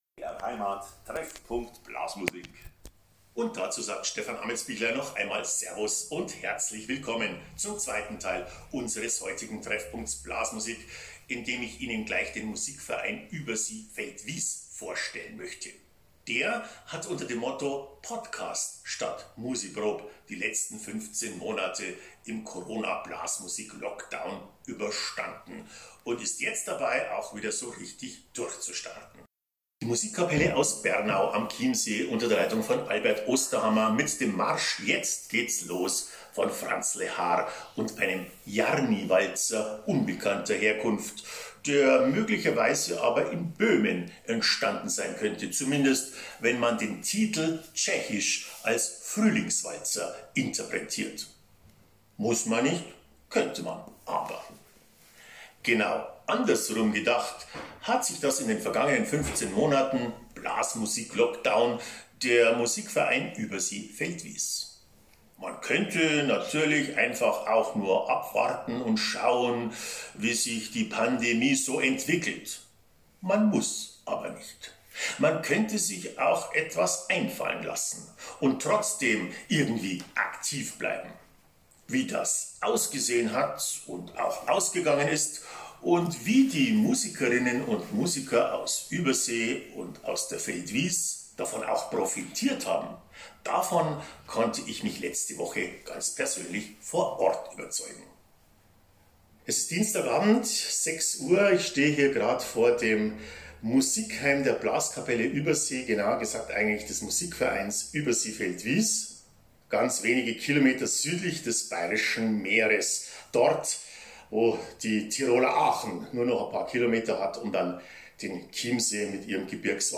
Interview BR Heimat